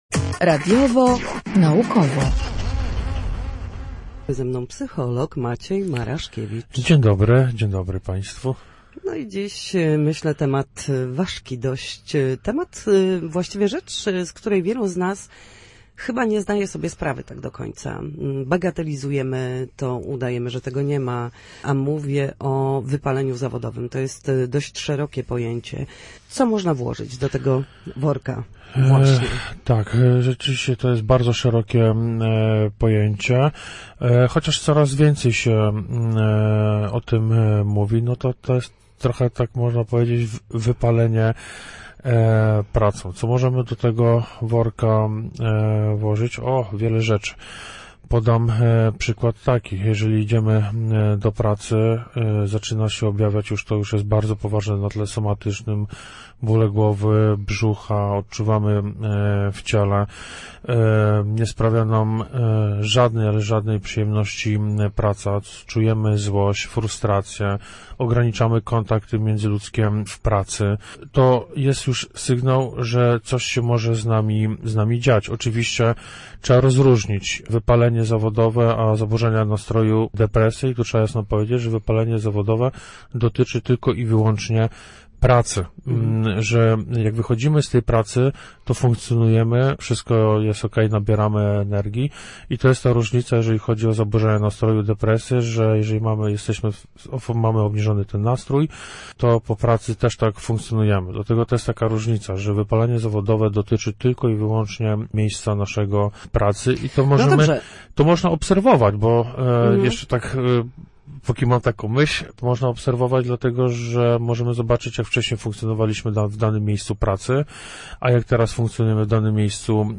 Psycholog mówił o tym, jak zadbać w pracy o swój dobrostan i emocje oraz jak oddzielać życie prywatne od zawodowego.